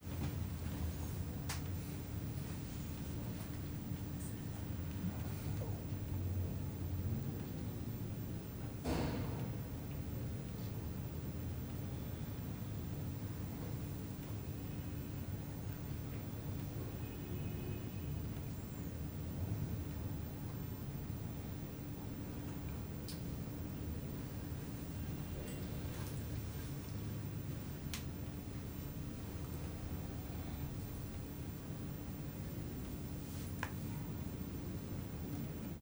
Ambiente interior de una habitación de una ciudad
ruido
Sonidos: Hogar
Sonidos: Ciudad